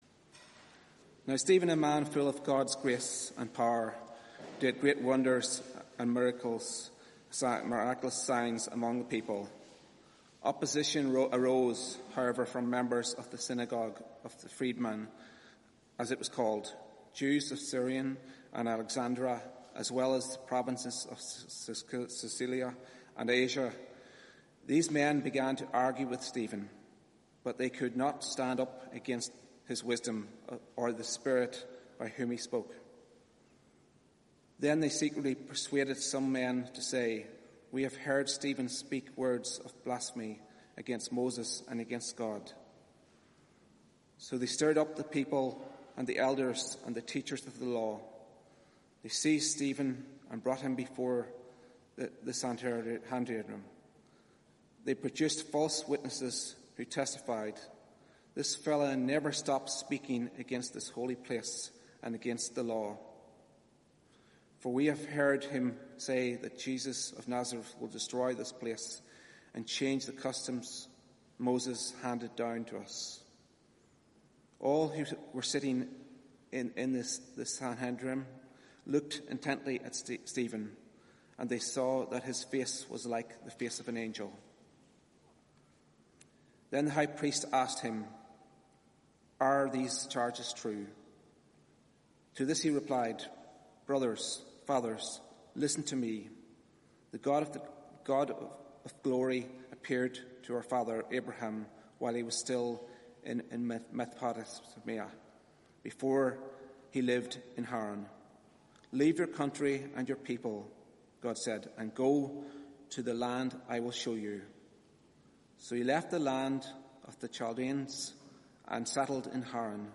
Media for 4pm Service on Sun 21st Jul 2019 16:00 Speaker